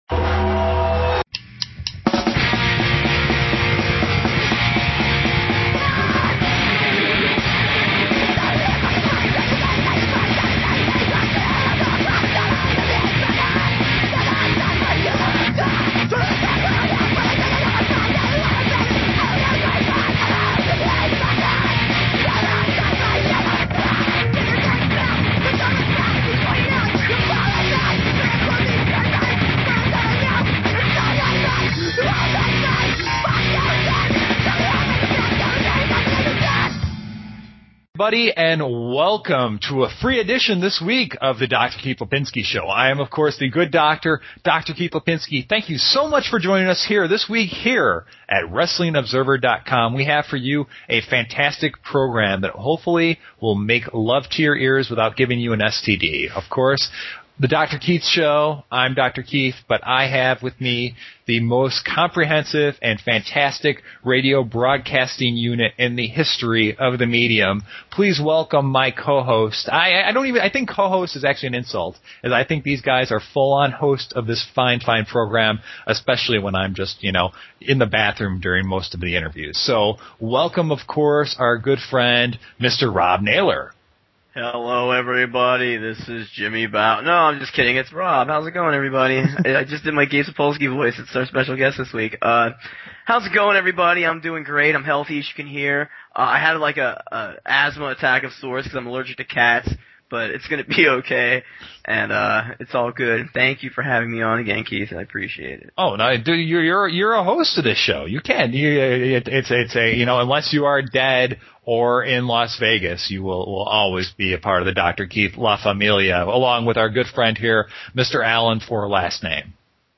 A really awesome interview